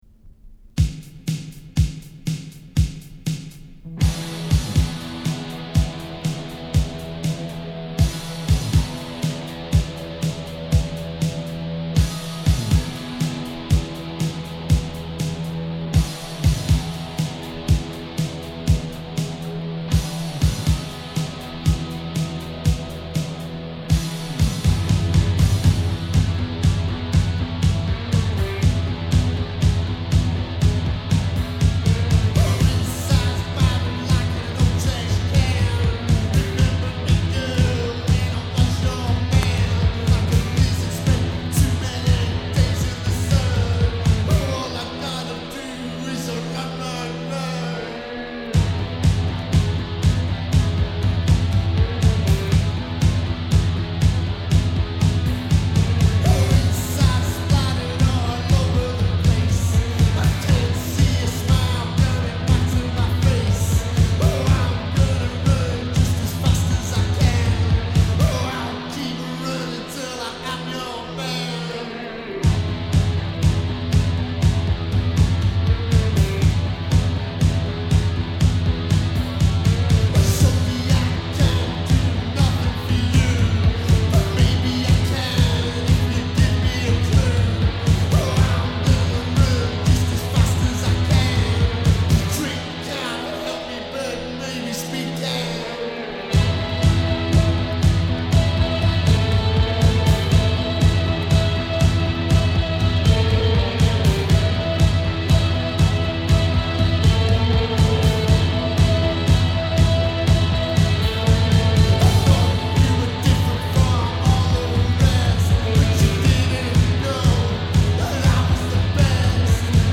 Electric Wah Guitars
Electric Snakeneck Guitars
Recorded at The Slaughter House, Yorks, 86